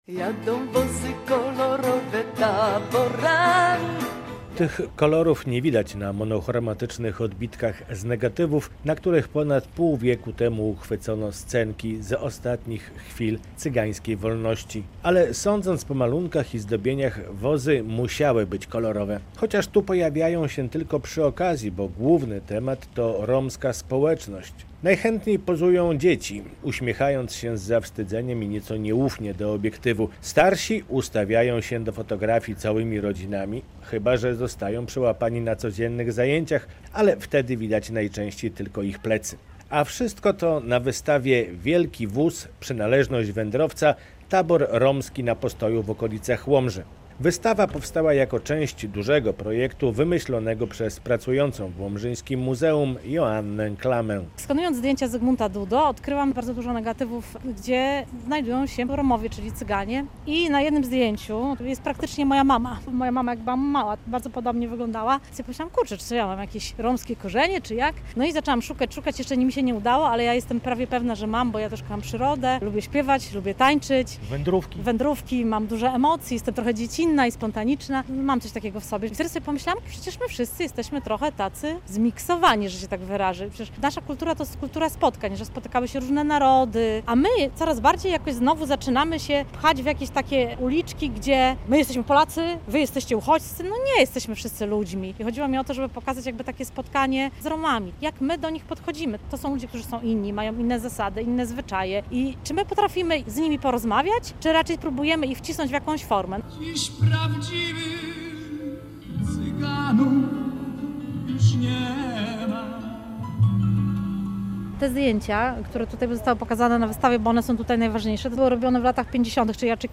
Cygańska wystawa w Łomży - relacja